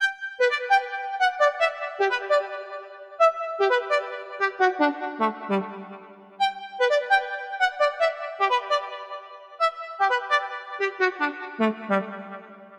150BPM Lead 08 Gmaj.wav